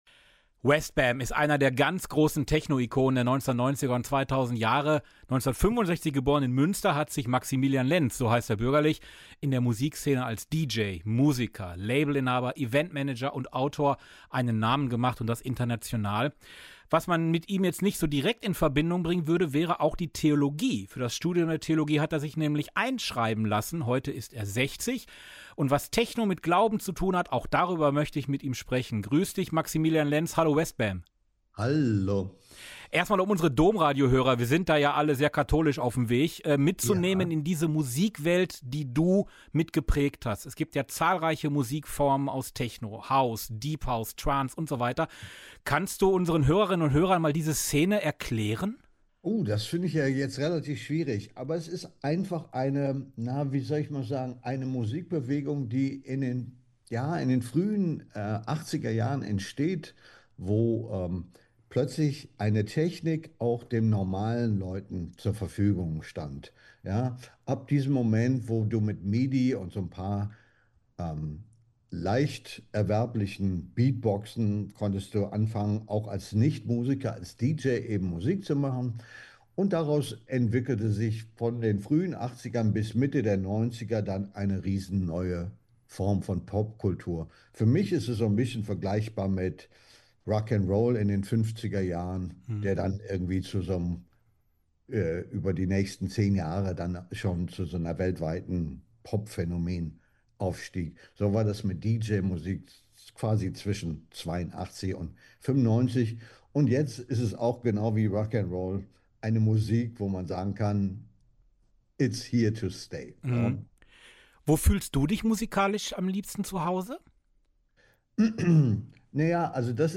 Ein Interview mit Westbam (bürgerlich Maximilian Lenz, DJ, Musiker, Labelinhaber, Eventmanager und Autor)